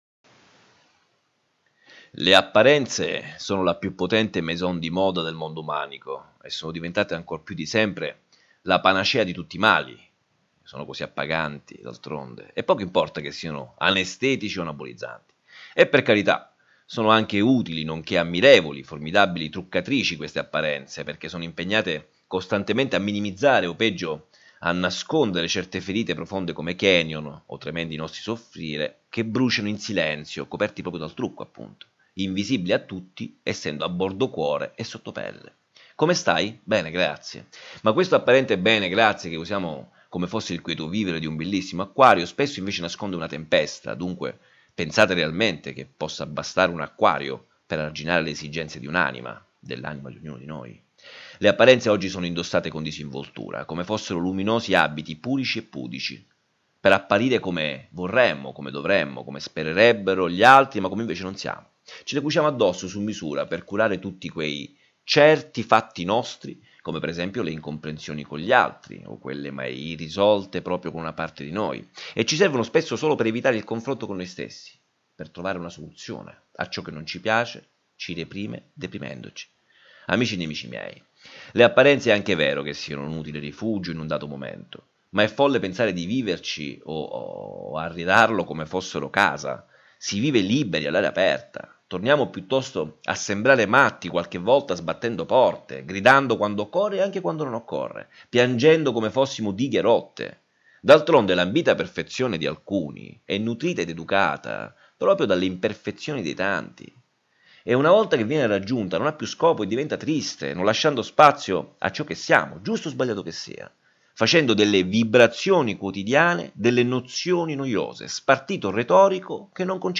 I minuti dei file audio riproducono le riflessioni degli articoli a voce alta, perché abbiano accesso all’ascolto i ciechi e quelli tra noi che pur avendo la vista sono diventati non vedenti,